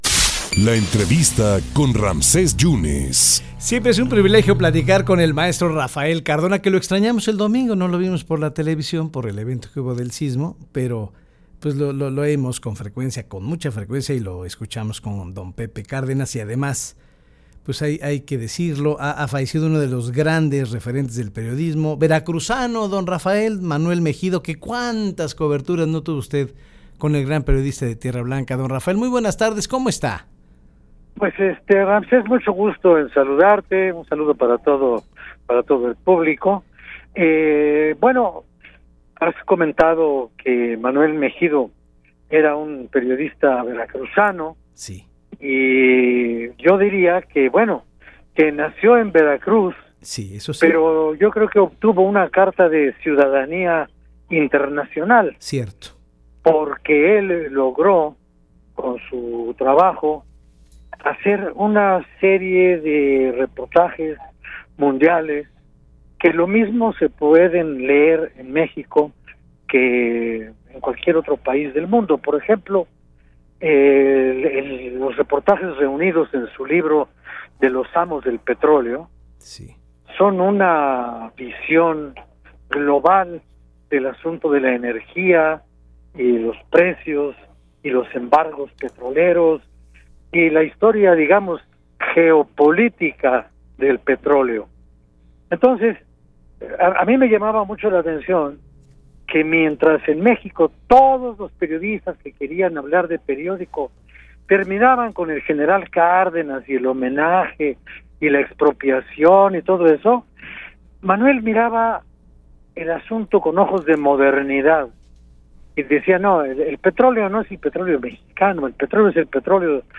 Redacción/Xalapa.- El maestro Rafael Cardona, platicó para En Contacto sobre el reciente fallecimiento del periodista veracruzano Manuel Mejido.